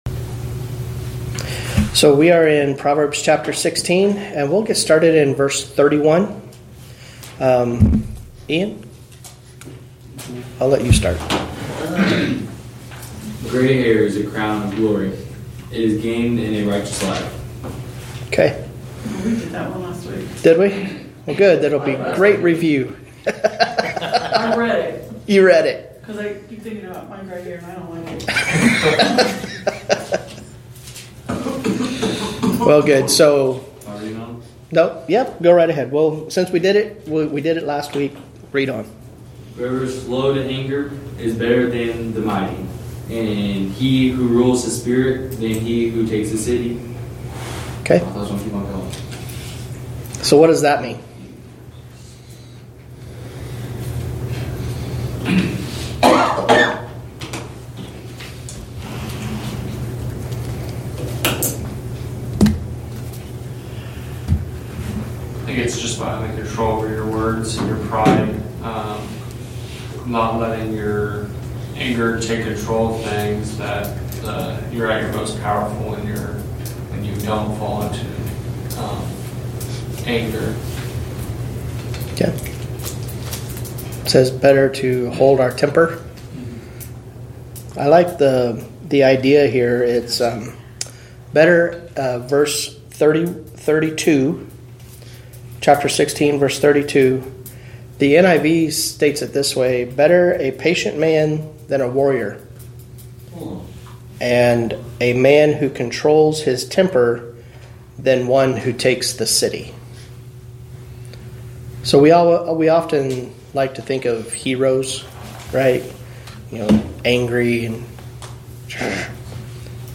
Wednesday Evening Bible Study Proverbs 16:32-17:5